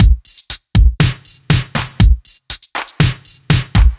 Index of /musicradar/french-house-chillout-samples/120bpm/Beats